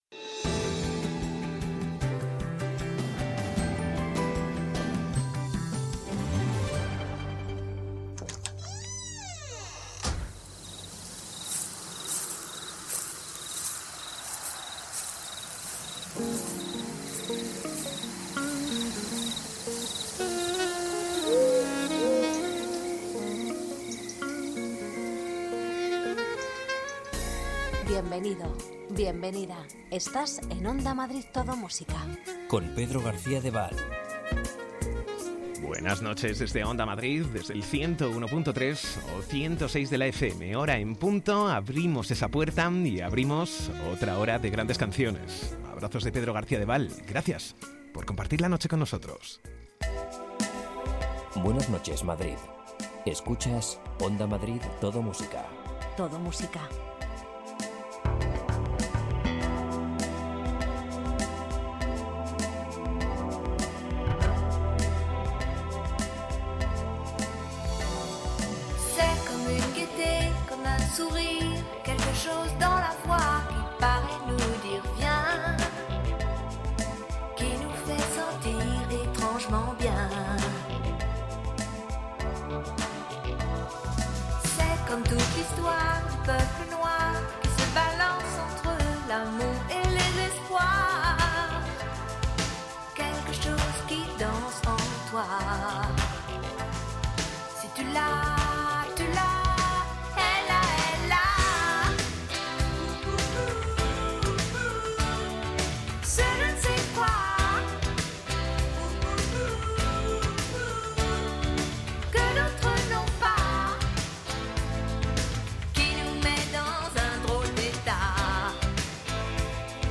Ritmo tranquilo, sosegado, sin prisas... Las canciones que formaron parte de la banda sonora de tu vida tanto nacionales como internacionales las rescatamos del pasado durante la madrugada.